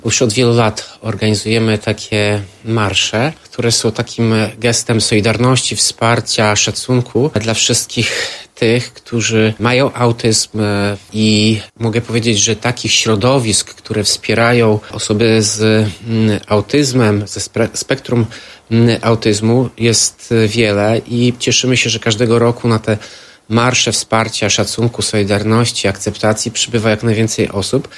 Mówił Tomasz Andrukiewicz, prezydent Ełku.